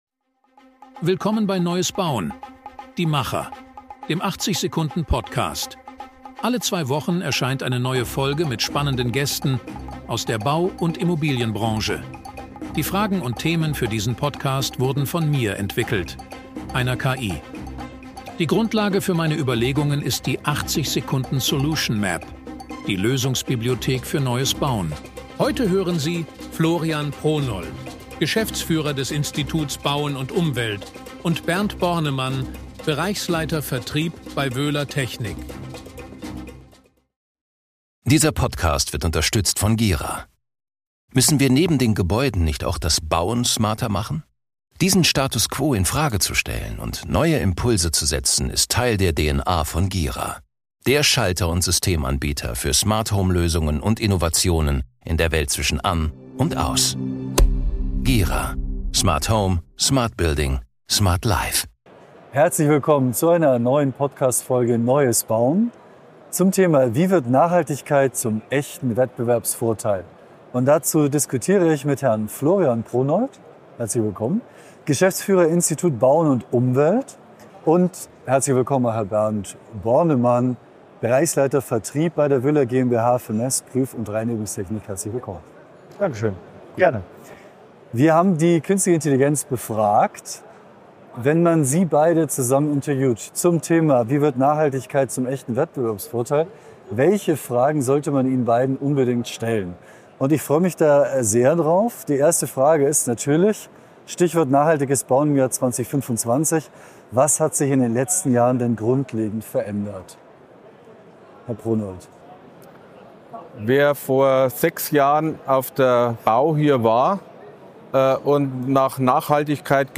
Ein Gespräch über Innovation, Praxis, Hindernisse – und über eine Bauwirtschaft im Wandel.